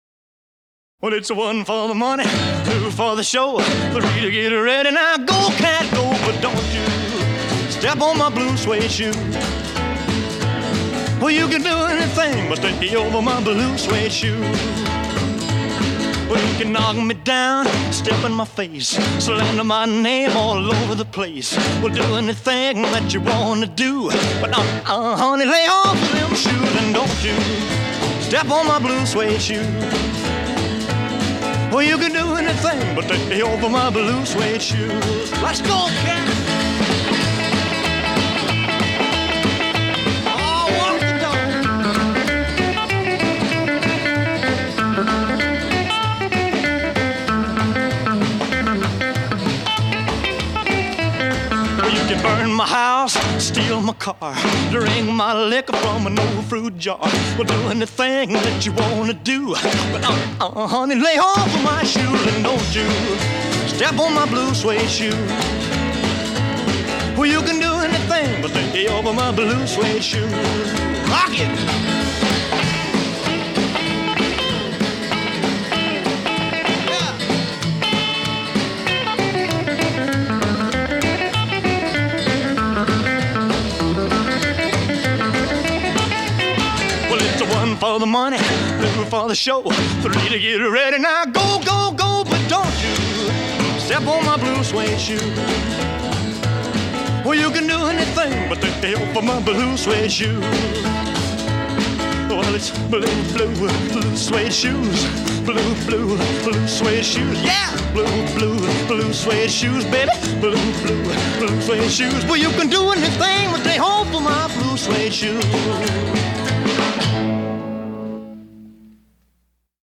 Rock and Roll, Rhythm and Blues, Country